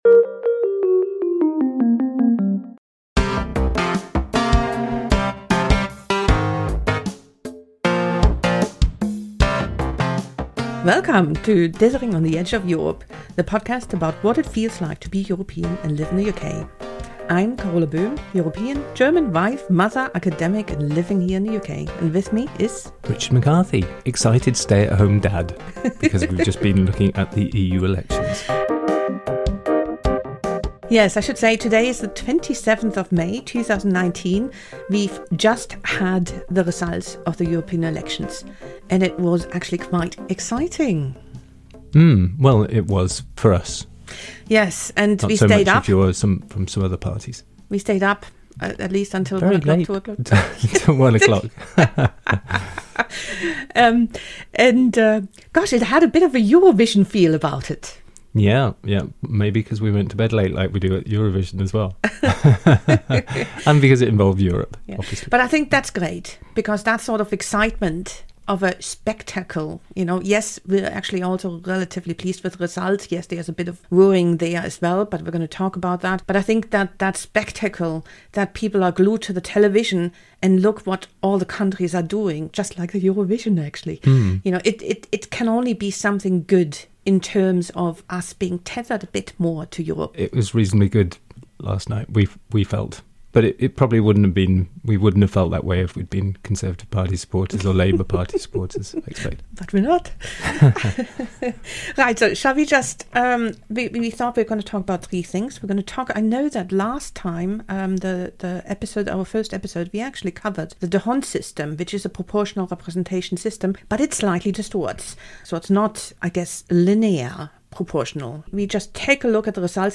still have clinking cups of tea